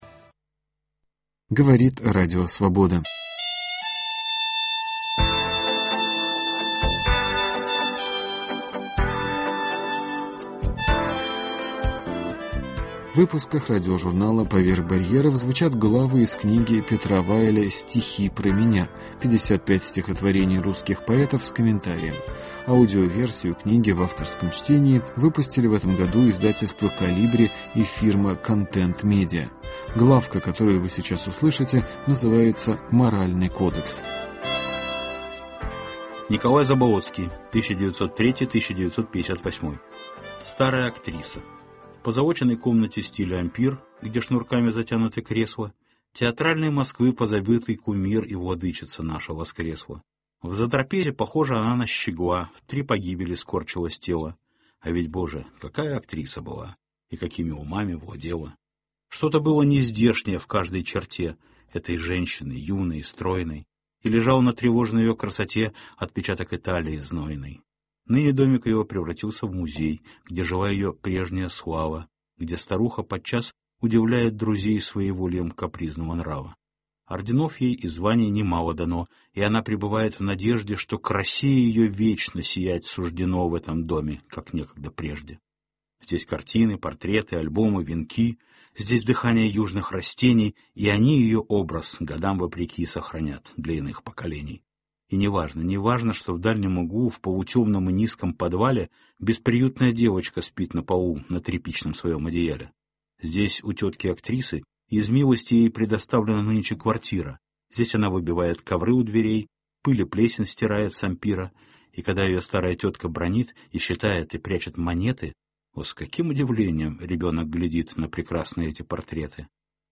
Петр Вайль читает главу из своей книги «Стихи про меня».